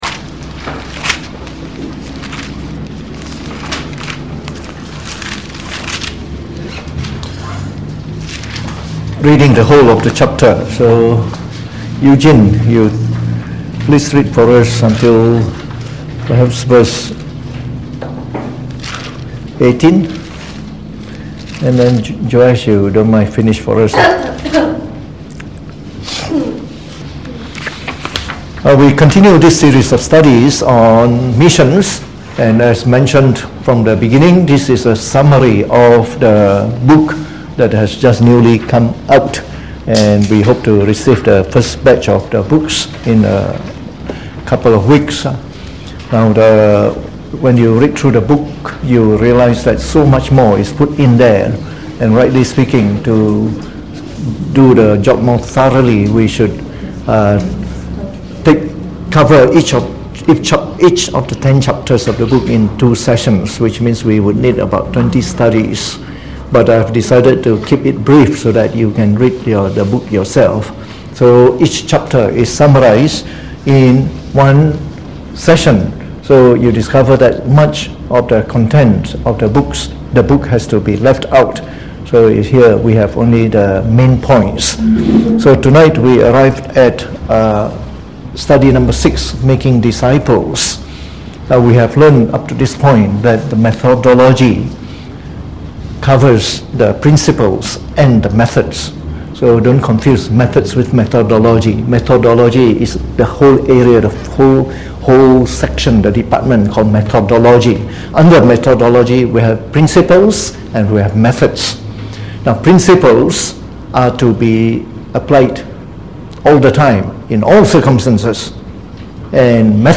Preached on the 9th of January 2019 during the Bible Study, from our series on Missions.